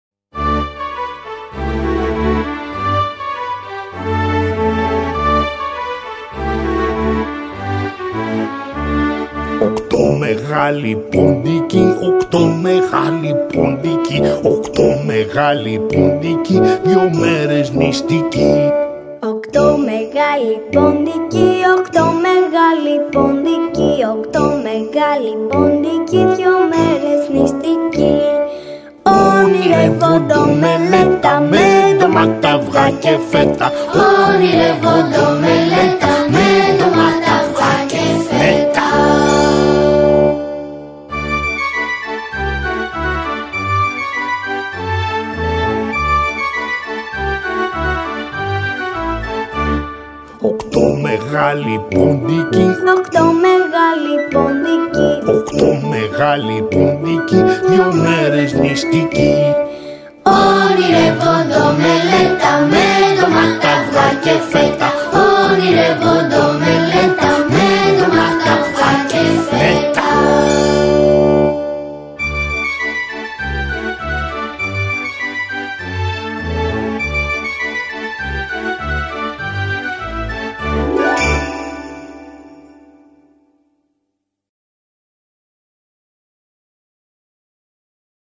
μελοποιημένα αποσπάσματα
αλλά και παιδιά δημοτικών σχολείων.